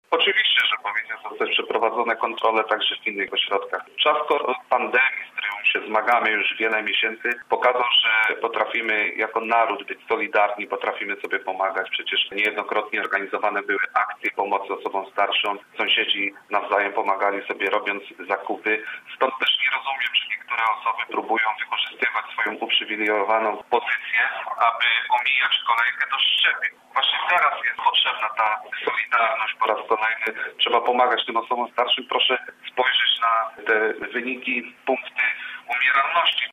Zdaniem naszego porannego gościa, podobne działania powinny być przeprowadzone także w innych jednostkach, które zajmują się szczepieniami: